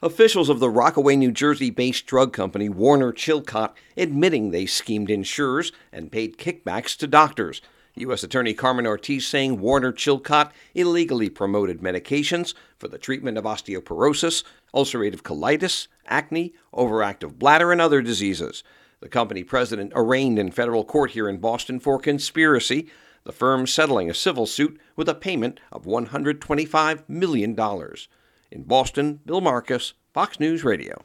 REPORTS FROM BOSTON: